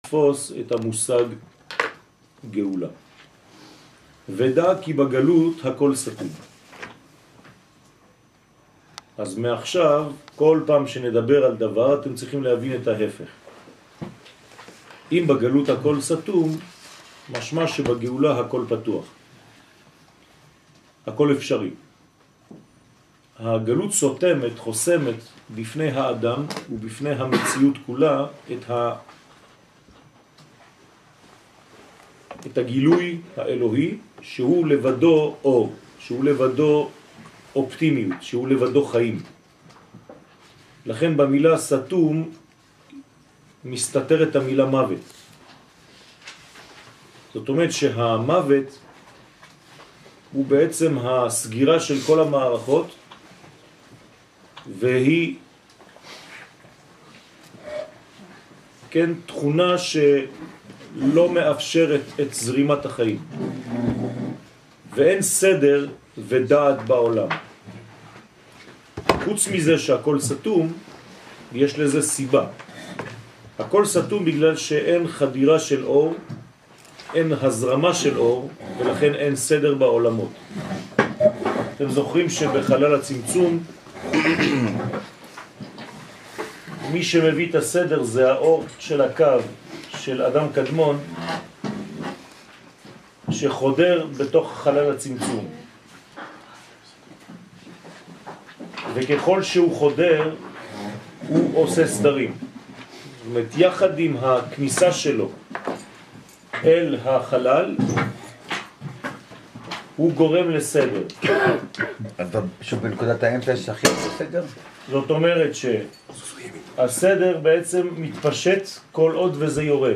שיעור על גאולה
שיעור-על-גאולה.mp3